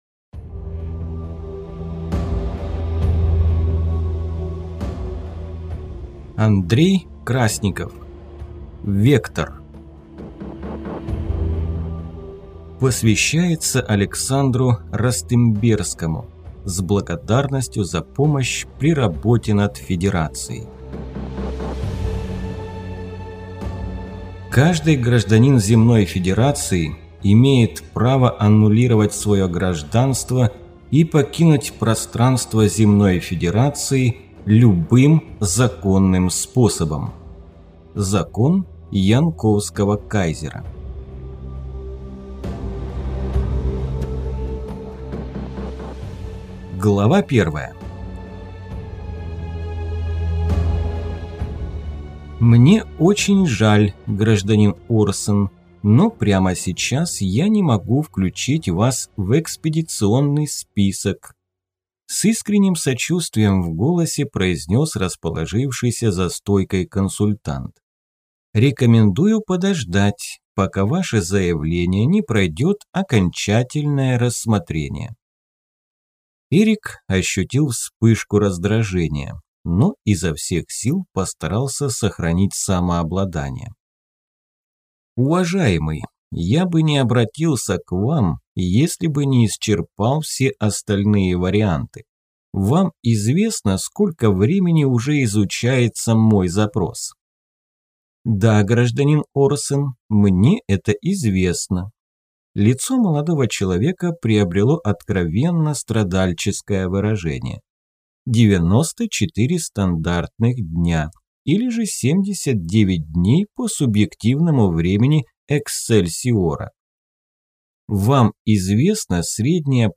Аудиокнига «Вектор» Андрей Красников в интернет-магазине КнигоПоиск ✅ в аудиоформате ✅ Скачать Вектор в mp3 или слушать онлайн
Прослушать фрагмент аудиокниги Вектор Андрей Красников Произведений: 16 Скачать бесплатно книгу Скачать в MP3 Вы скачиваете фрагмент книги, предоставленный издательством